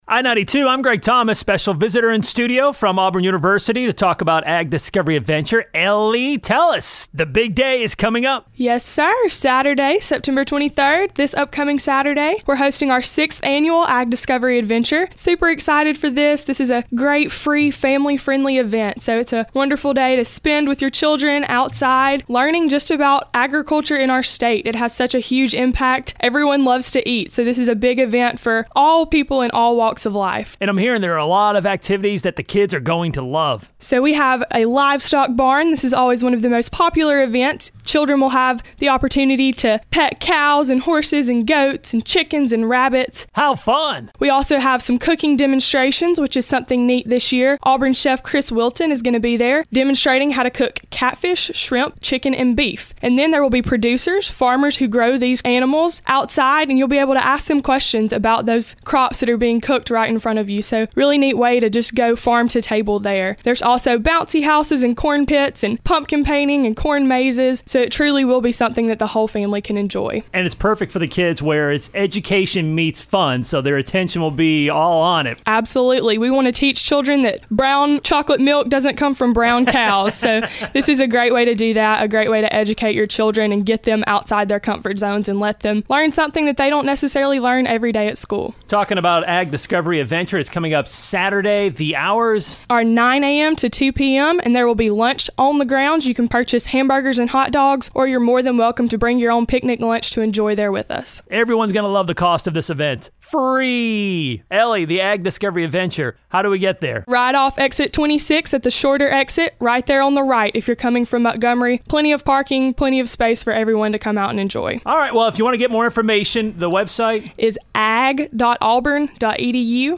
Auburn Ag Discovery Adventure 2017 Preview [INTERVIEW]